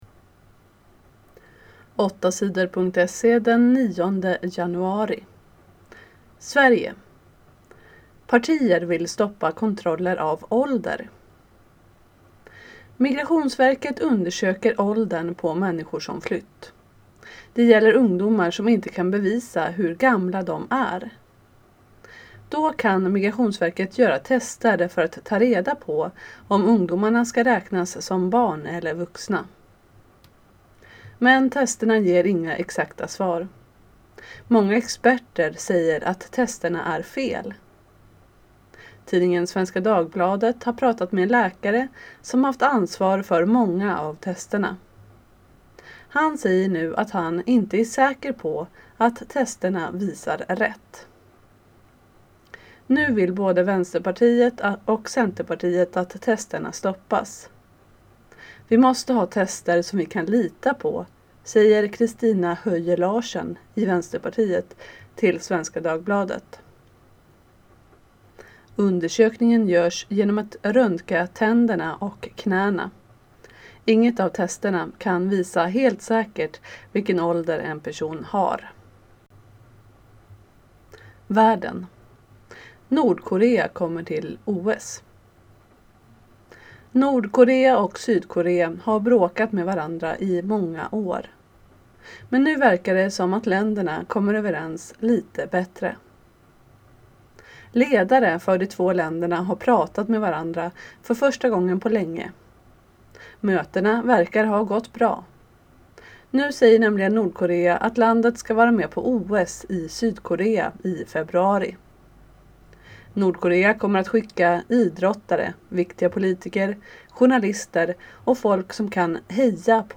8 Sidors nyheter den 9 februari